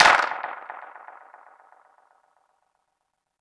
• Clap Single Shot F# Key 34.wav
Royality free clap sample - kick tuned to the F# note. Loudest frequency: 2040Hz
clap-single-shot-f-sharp-key-34-JfS.wav